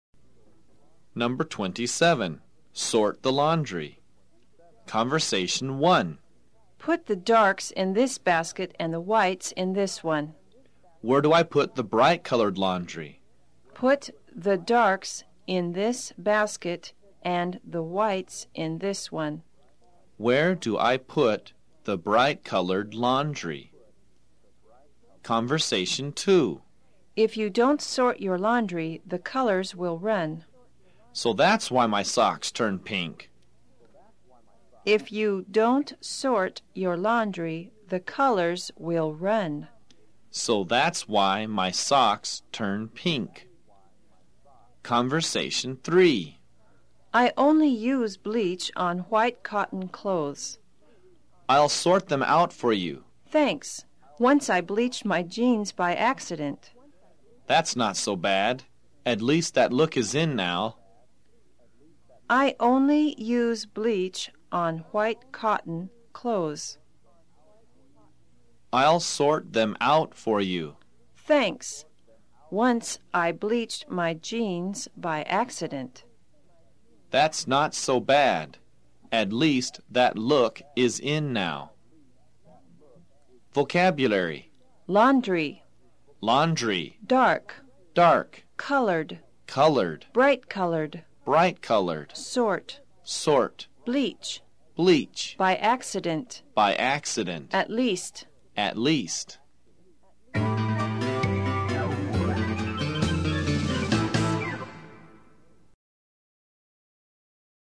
在线英语听力室快口说英语027的听力文件下载,快口说英语的每一句话都是地道、通行全世界的美国英语，是每天24小时生活中，时时刻刻都用得上的美语。